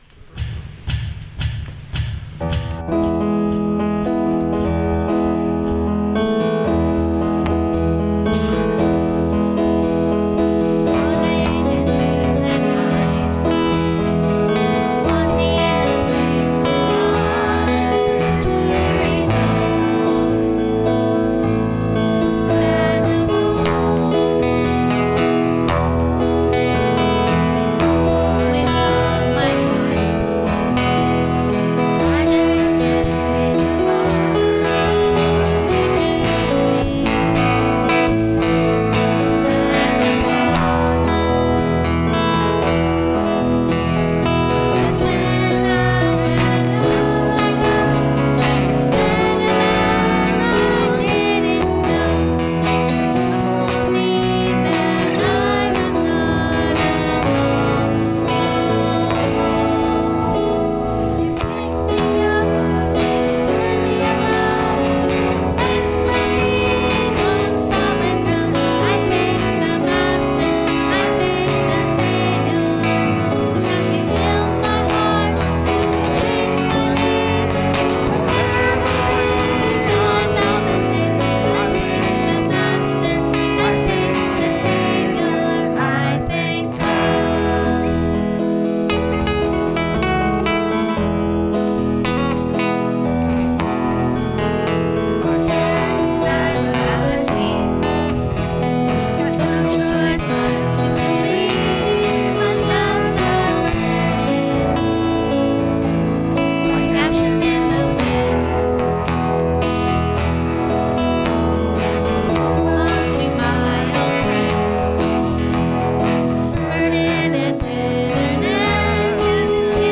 This is a raw and rough recording of the songs as we presented them. This recording is to assist the musicians in their prep and allow them to hear what we sort of sounded like as best as we can in such a raw setting.